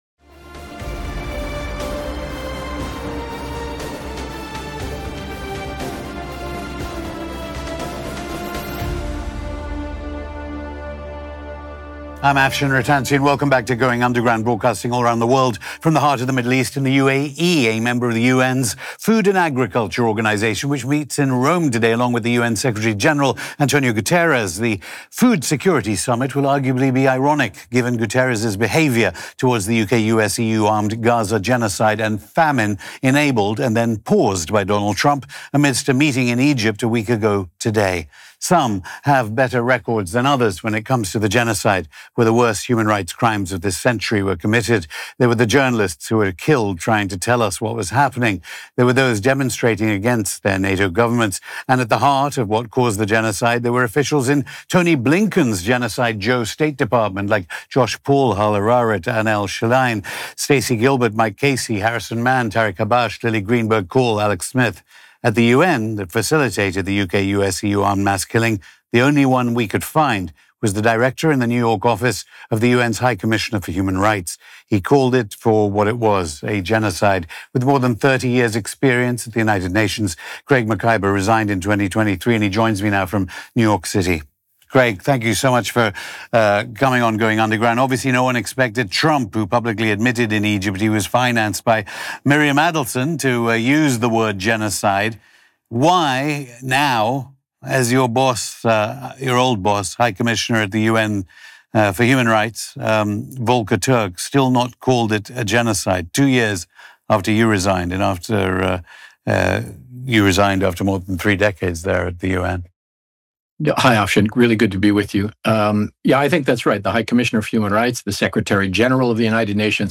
Hosted by Afshin Rattansi